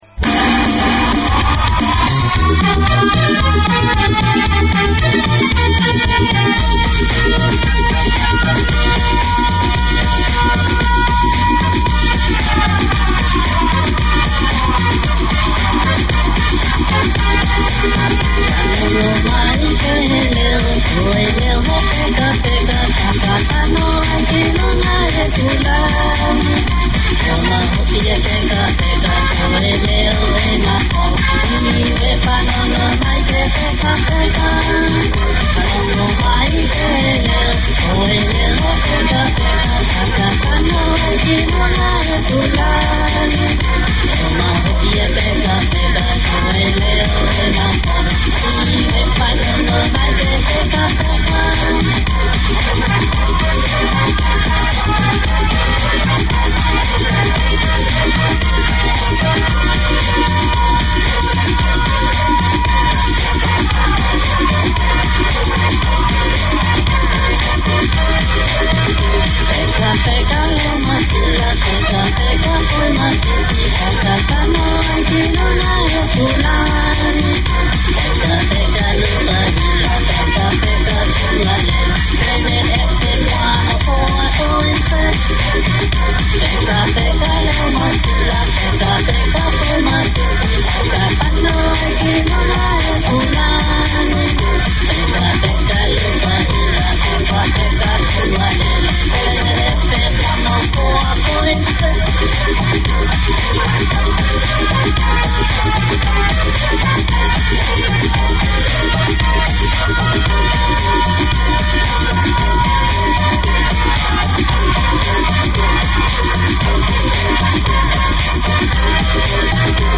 Community Access Radio in your language - available for download five minutes after broadcast.
A combination of modern and organic motifs, Art Deco design continues to be captivating and collectable. Deco is the passion of the presenters of this programme that explores the local and global Deco scene, preservation and heritage, the buildings, jewellery and furnishings with interviews, music, notice of coming events; a sharing of the knowledge of Club Moderne, the Art Deco Society of Auckland.